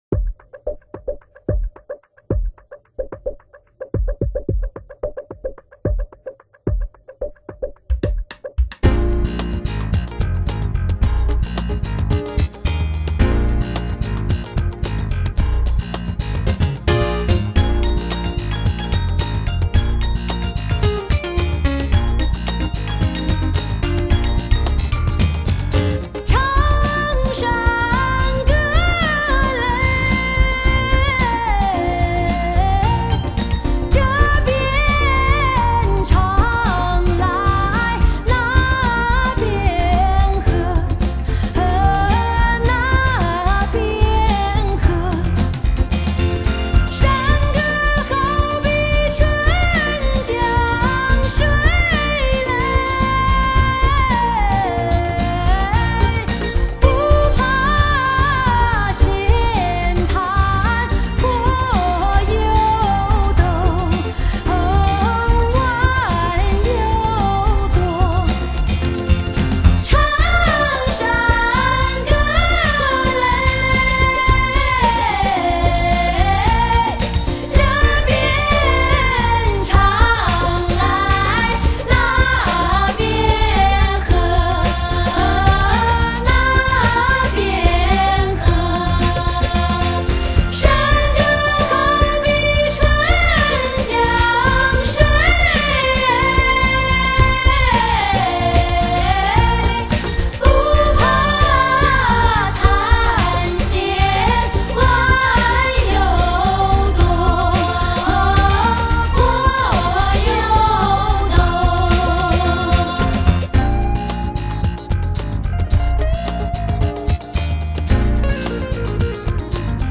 中国最好听的声音 经典民谣 发烧真声 充满磁性
其精髓在忠于中国传统音乐文化的基础上融入西方不同地域的音乐文化特色，如爵士、布鲁斯、拉丁和华尔兹等。